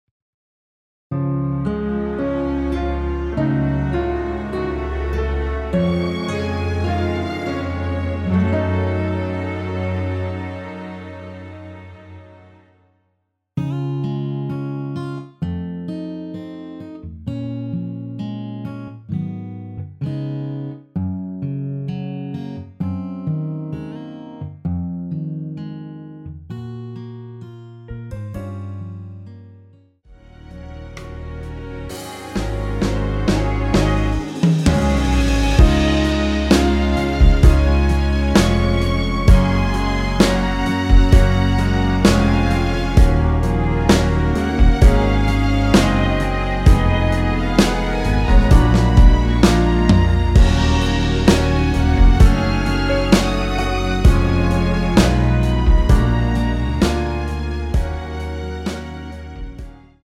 원키에서(-1)내린 MR입니다.
Db
앞부분30초, 뒷부분30초씩 편집해서 올려 드리고 있습니다.